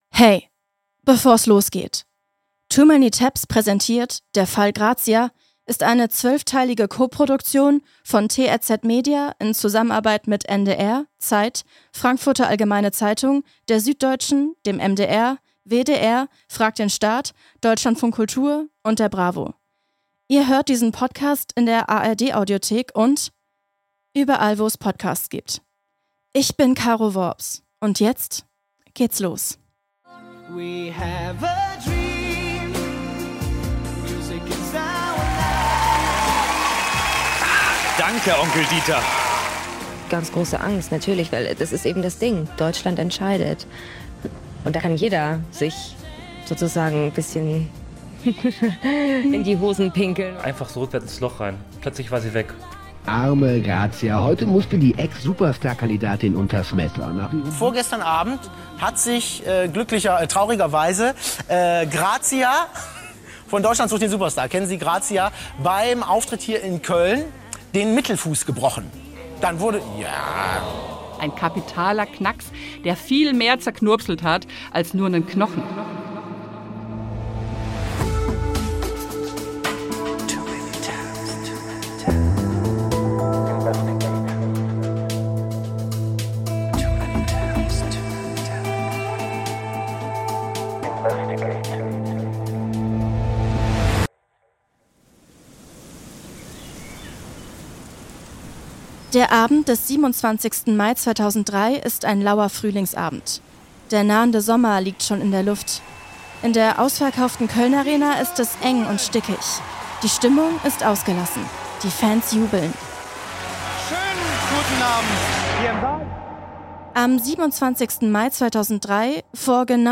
Eine fulminante Storytelling-Folge und Co-Produktion etlicher Medienmarken.